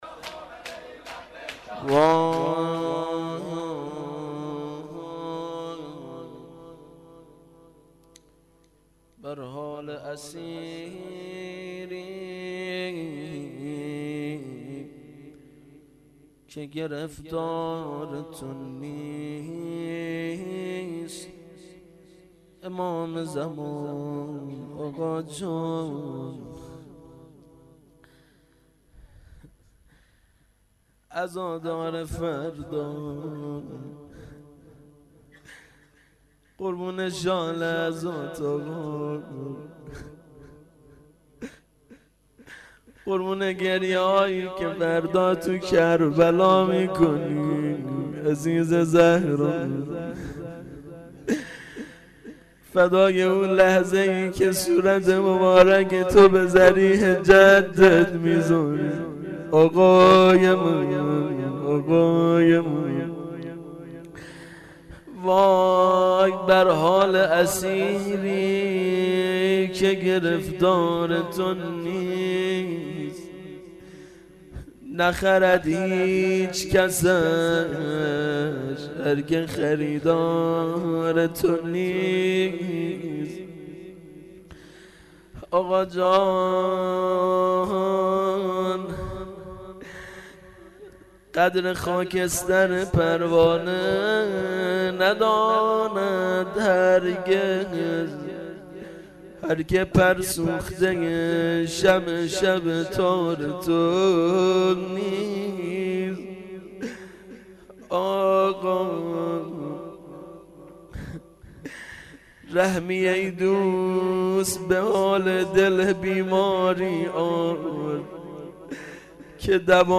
مناجات.mp3